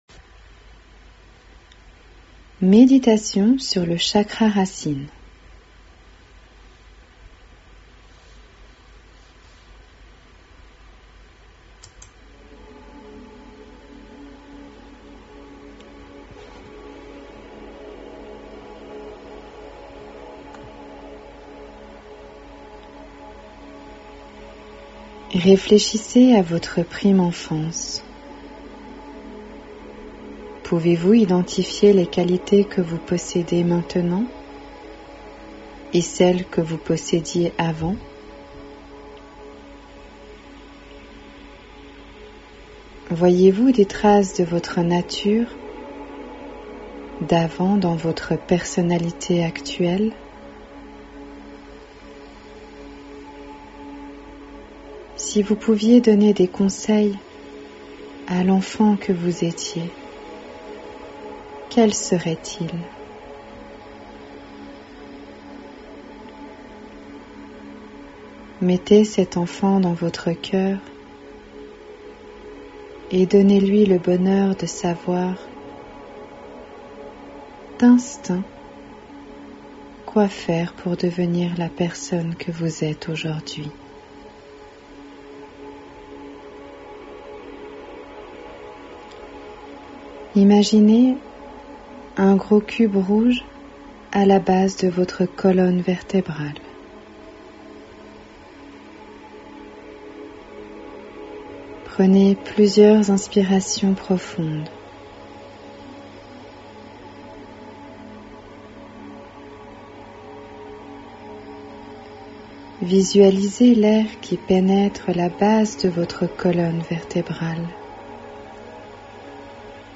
Ma méditation chakra racine
meditation-chakra-racine-avril2021.mp3